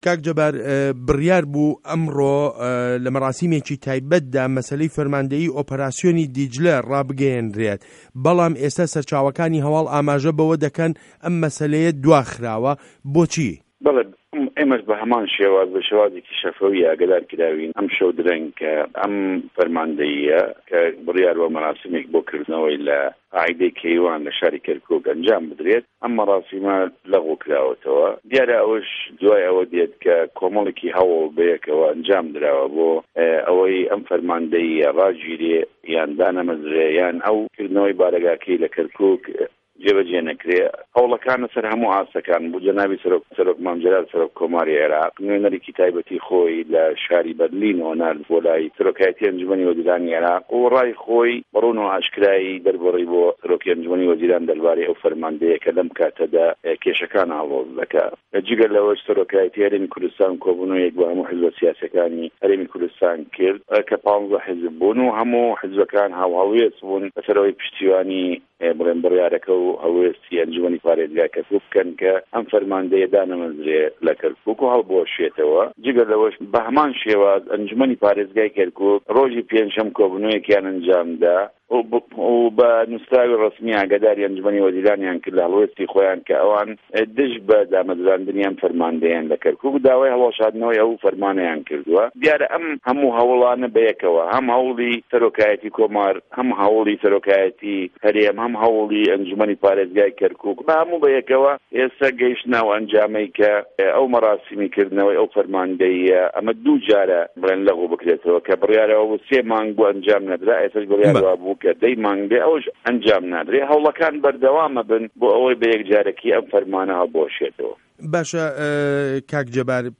وتووێژ له‌گه‌ڵ جه‌بار یاوه‌ر